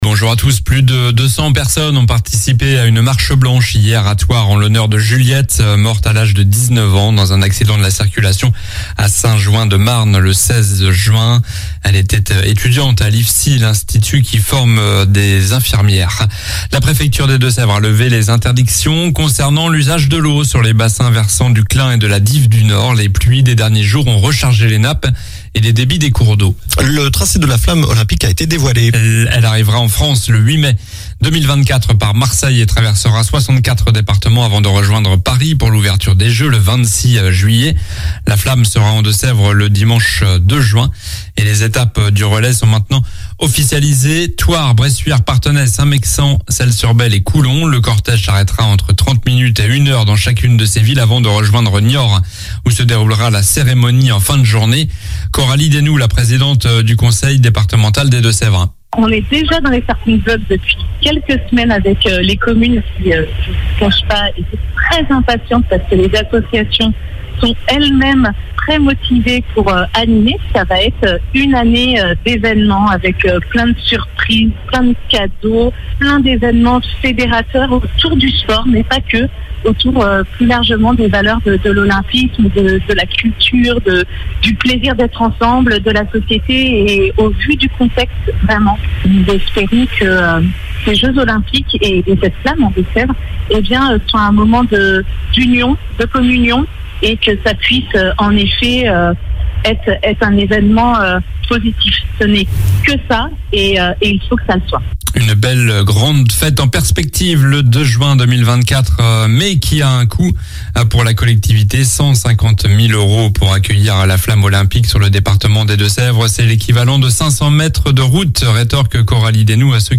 Journal du samedi 24 juin (matin)